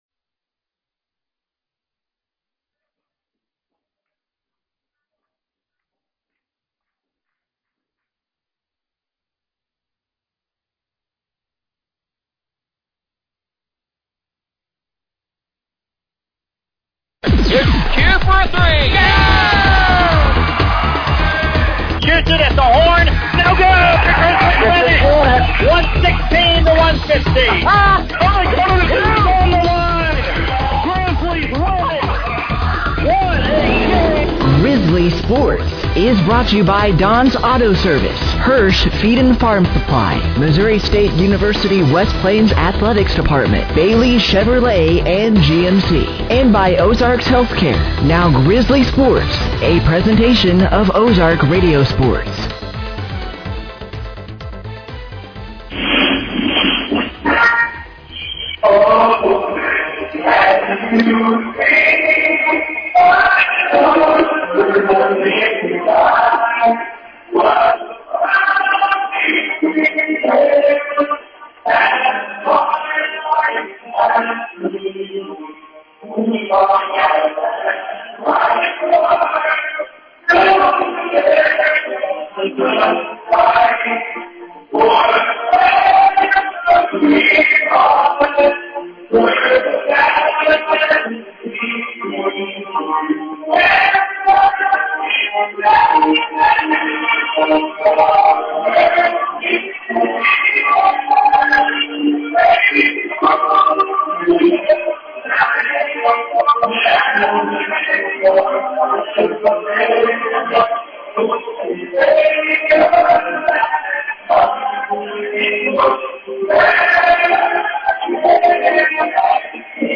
The Missouri State West Plain Grizzlies were back in action on Wednesday night, January 21st, 2026 as they traveled to the Libla Family Sports Complex @ Poplar Bluff, Missouri, taking on The Three Rivers Raiders.
Game Audio Below: